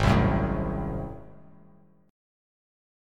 Listen to Ab7b9 strummed